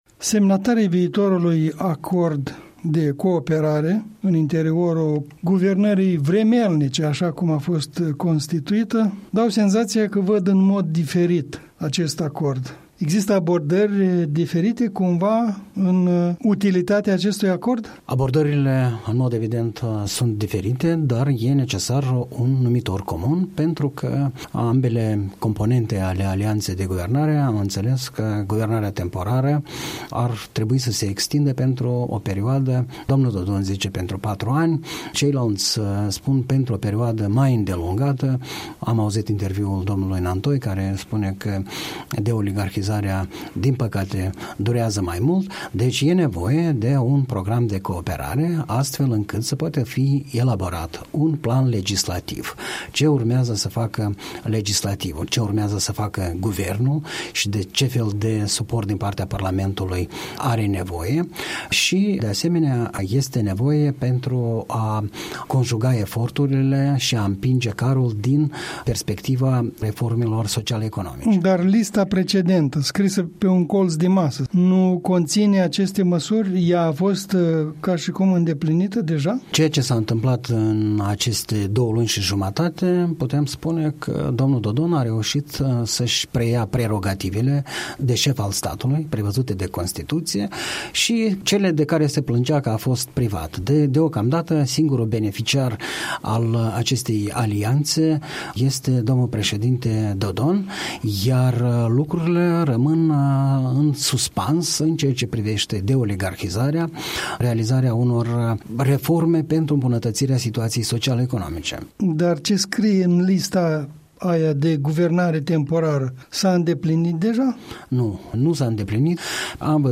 Un comentariu săptămânal în dialog la Europa Liberă.